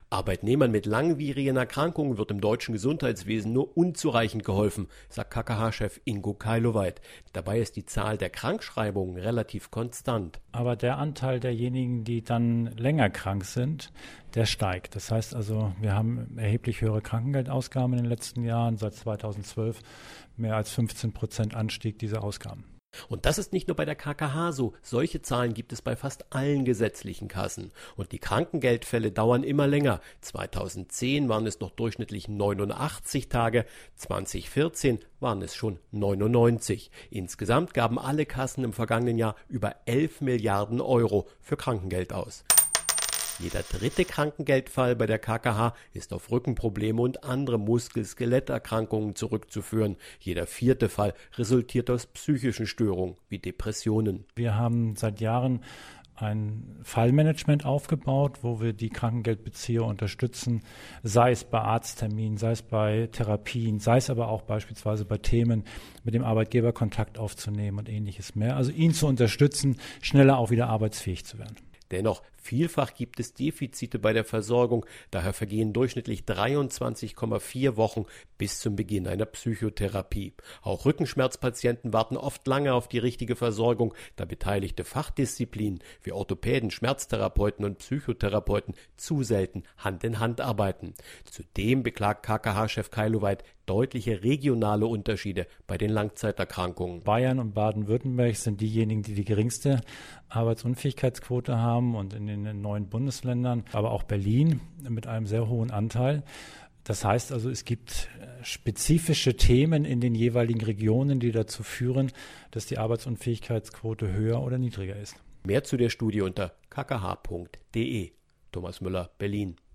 Allgemein, O-Töne / Radiobeiträge, Politik, Ratgeber, , ,